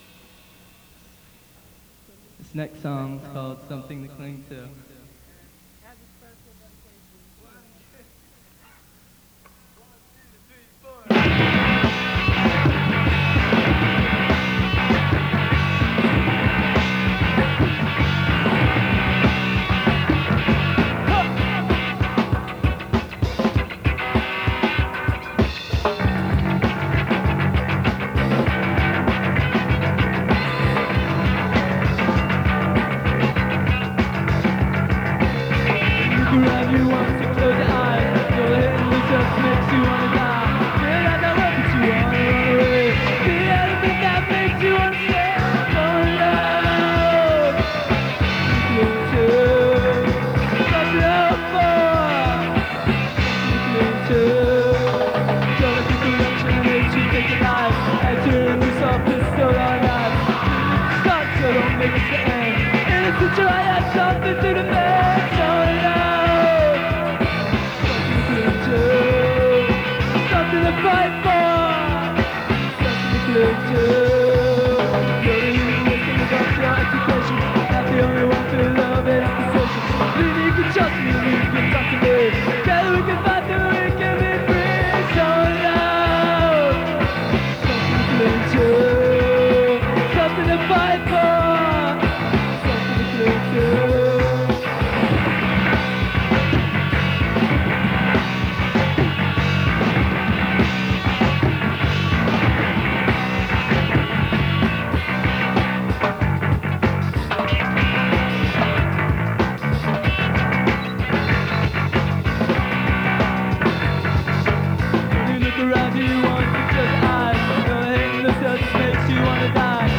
heavy Reggae influence